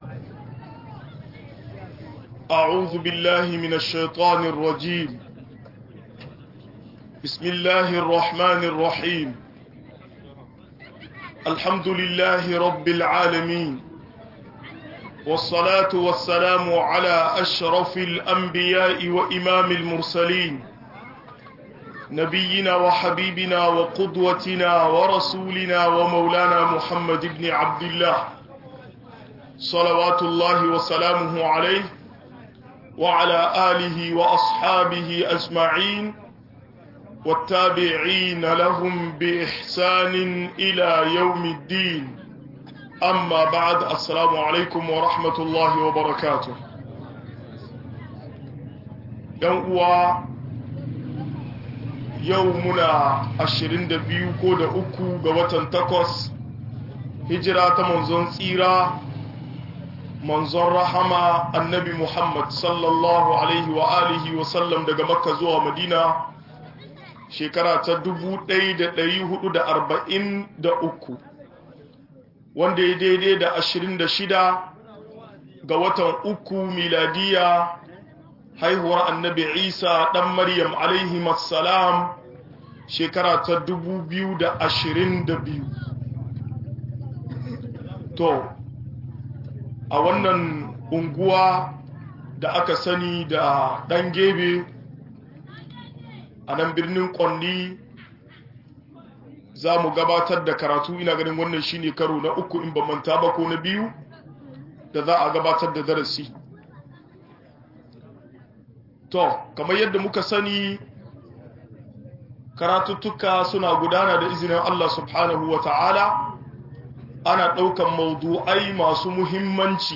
Siffofin da Manzon Allah ya kebanta da su - MUHADARA